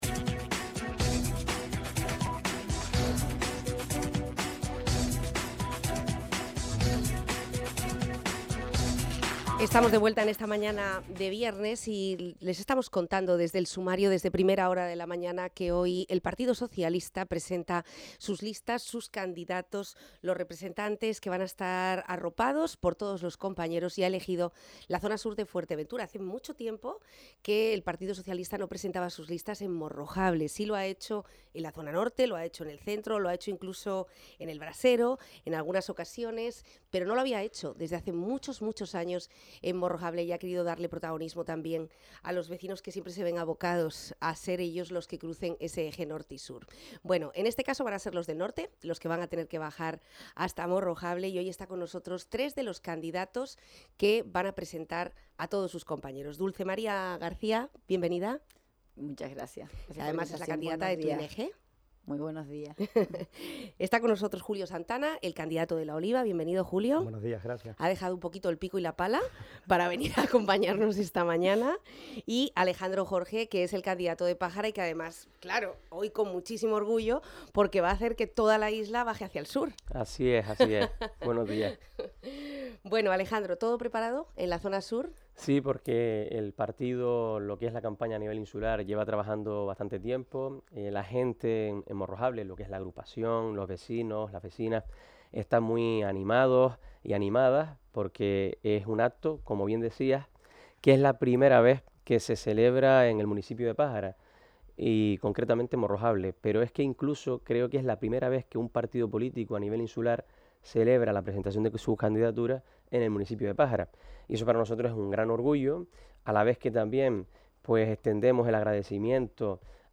PSOE Fuerteventura presenta sus listas electorales en Morro Jable. Y la entrevista completa se puede escuchar aquí: